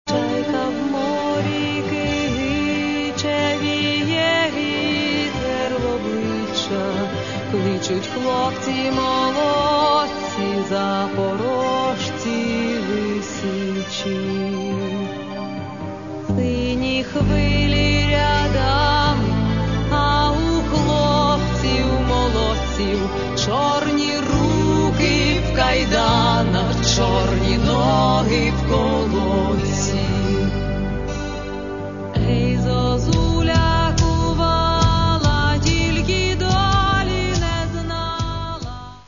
в прозрачных, нежных, женственных его проявлениях